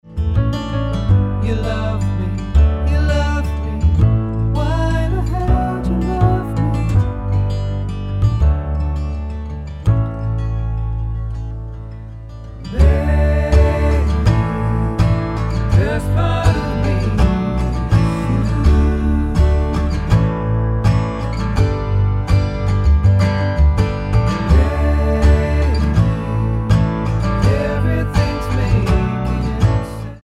Tonart:D mit Chor
Die besten Playbacks Instrumentals und Karaoke Versionen .